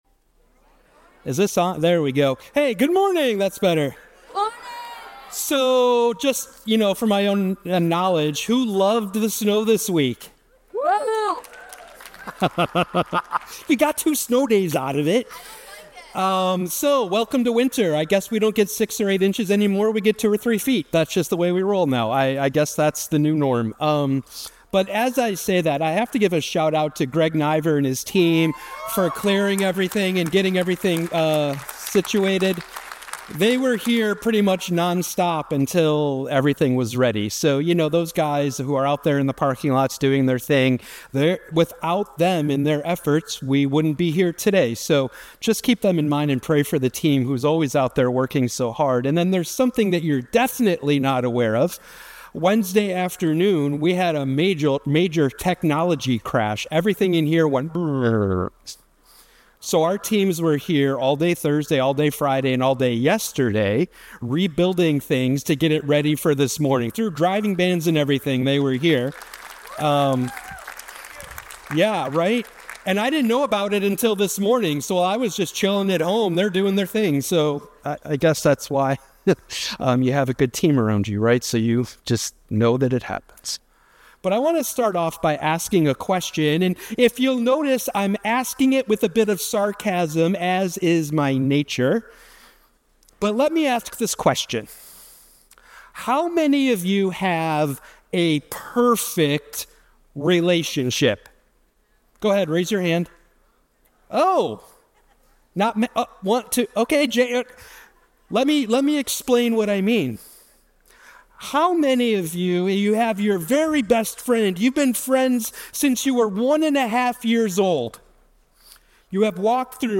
God wants us to follow Him wholeheartedly in every season of our lives. Watch Listen Go Deeper Sermon Discussion Guide To Be Continued... reading plan More